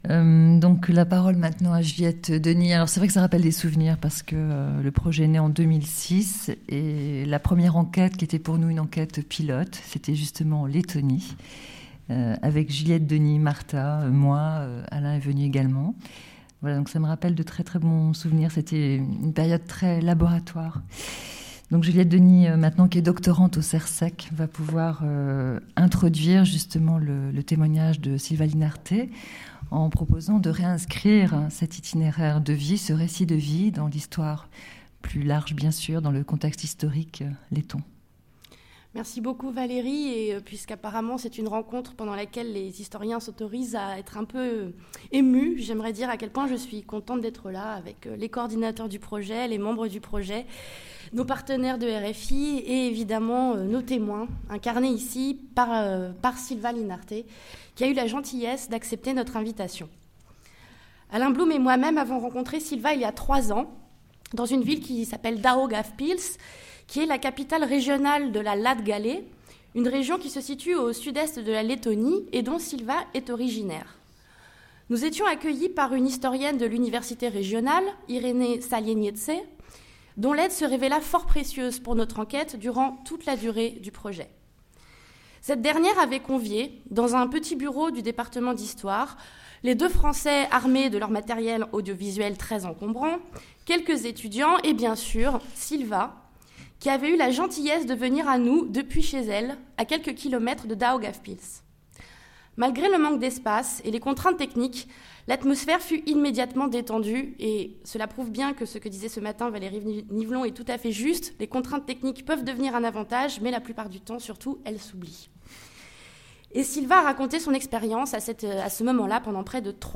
Cette journée d'étude est coordonnée par les missions Action culturelle et bibliothèque numérique de la BULAC et le CERCEC, en partenariat avec RFI et les éditions Autrement, avec le concours de la mission Communication externe de la BULAC.